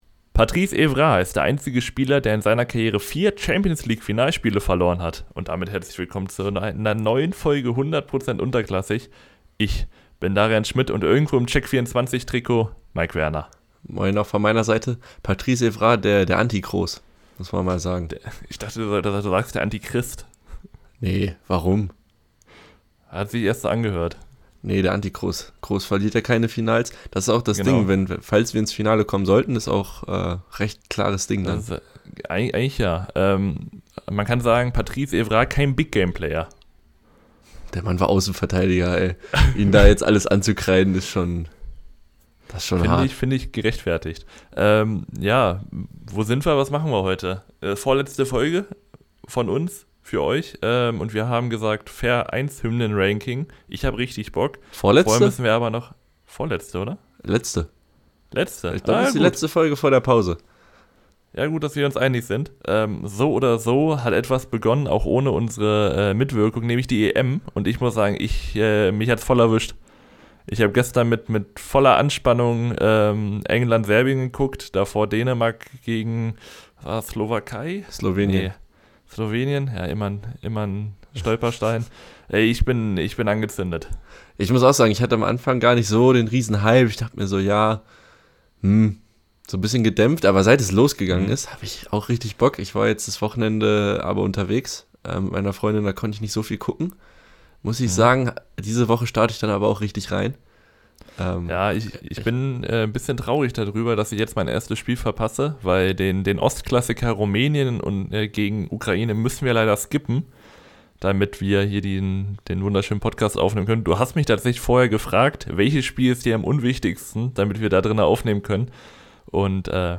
Wenn ich mir ein perfektes Duo bauen müsste, wäre es die letzte Folge vor der Sommerpause und ein Hymnenranking. Nicht nur, dass man hier den begrenzten Wissensschatz von uns sieht, nein, auch gesanglich ist es ausbaufähig.